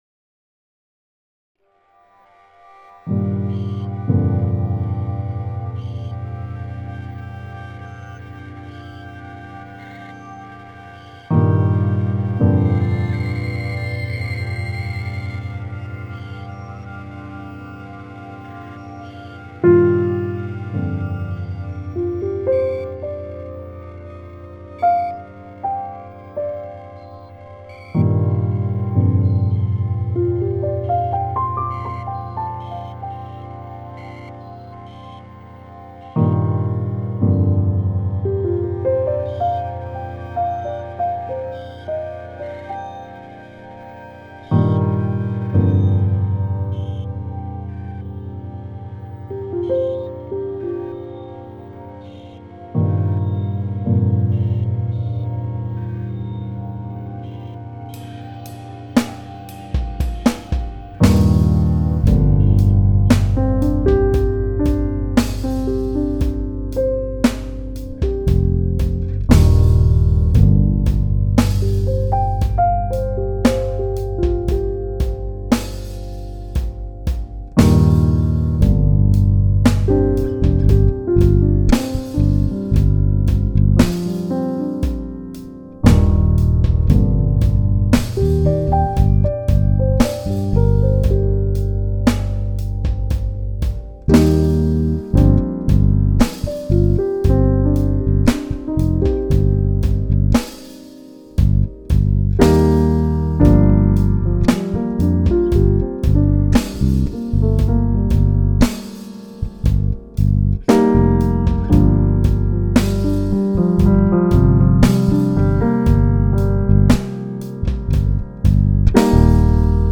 Jeden Tag im Oktober ein Musikstück für die richtige Halloween-Stimmung.